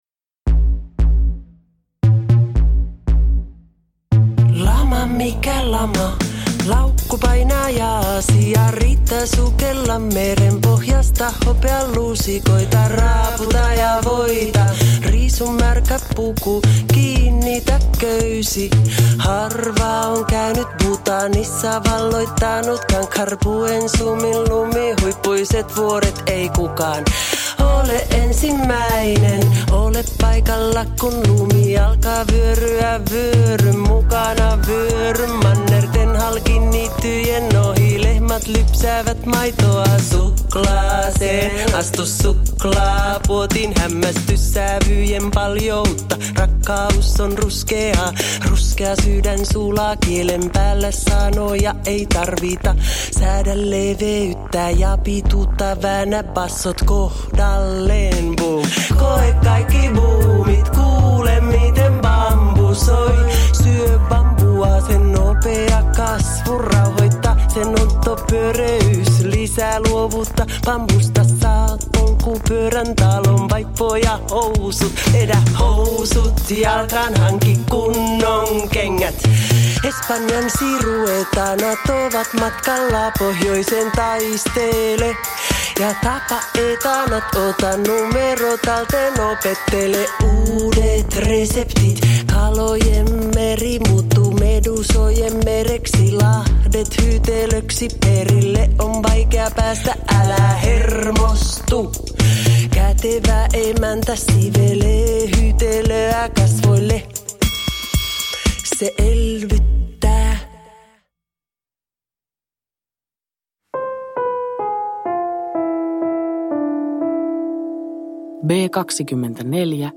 Kuuluuko tämä teille – Ljudbok – Laddas ner